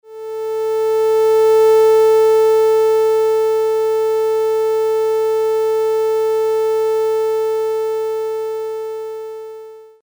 pic 11a)-c): a saw waveform a)nonmodulated, b)amplitudemodulated and c) pitchmodulated.
ampmod_saw.mp3